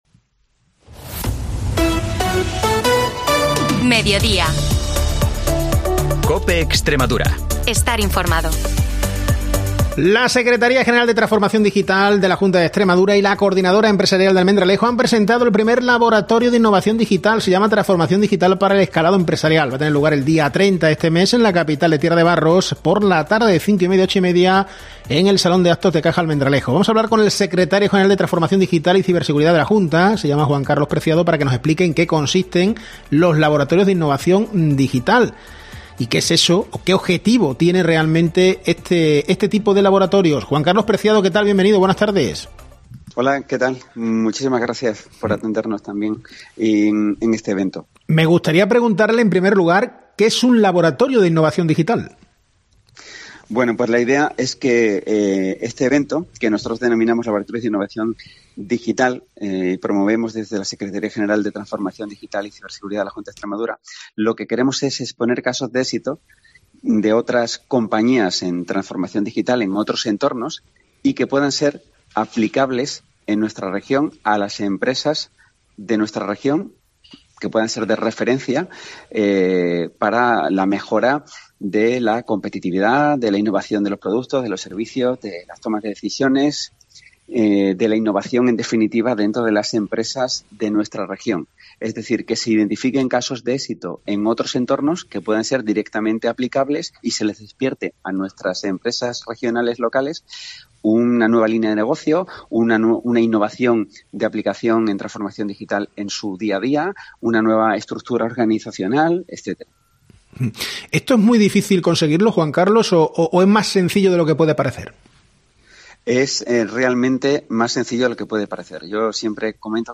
Información y entrevistas